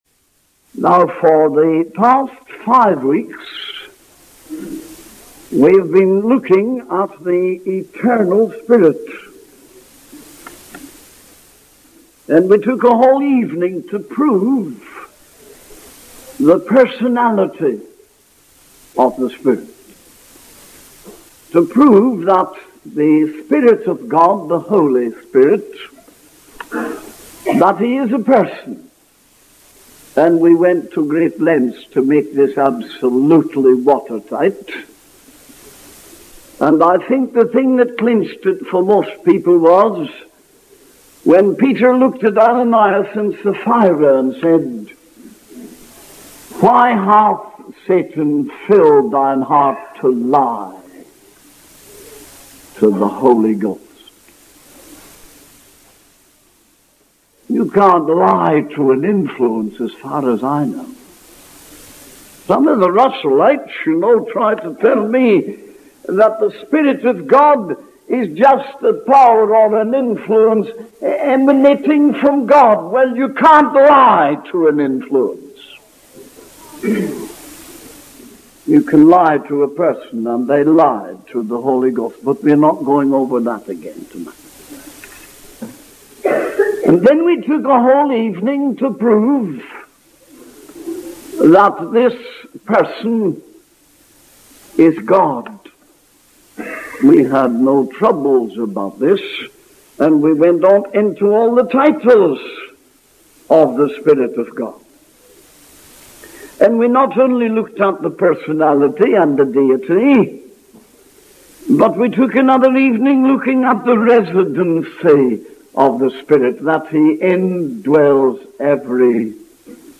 In this sermon, the speaker begins by referencing the book of Exodus and the anointing of Moses by the Lord. He then moves on to discuss the anointing of Jesus in the gospel of Luke, highlighting how Jesus was anointed with the Holy Spirit and power to preach the gospel and heal the oppressed.